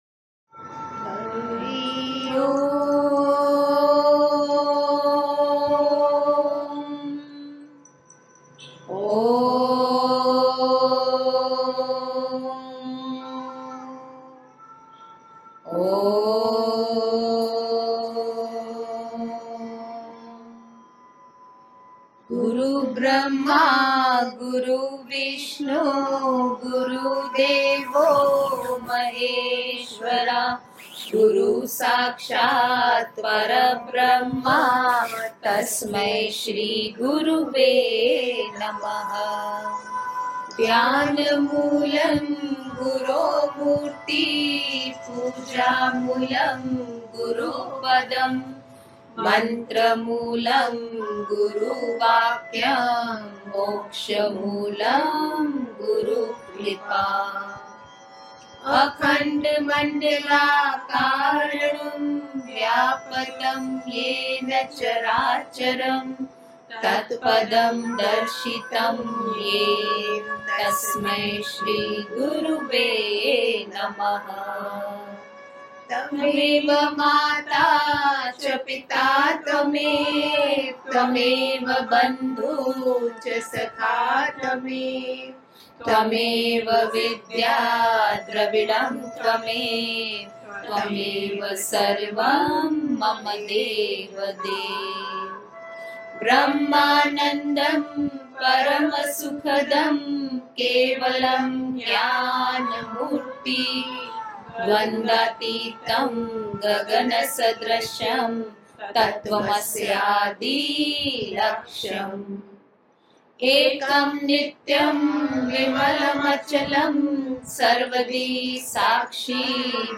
Daily morning Mantra